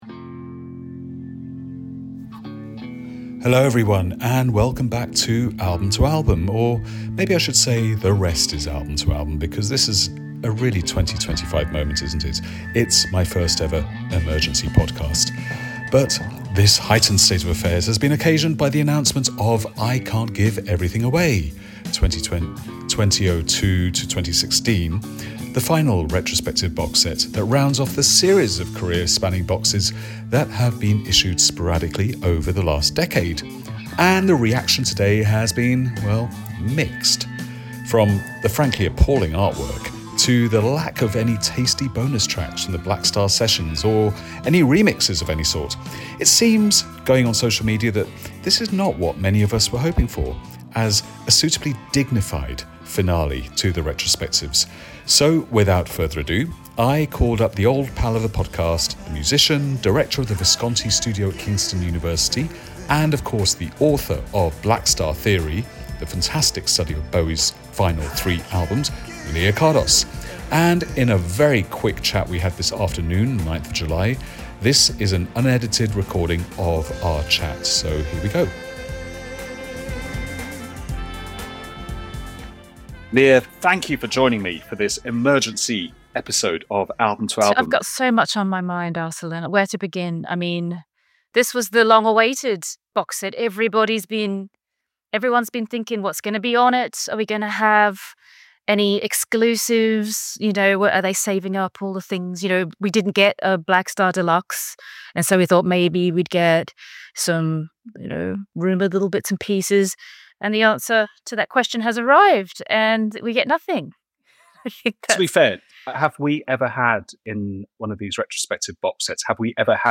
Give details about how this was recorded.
recorded fast and raw on 9 July 2025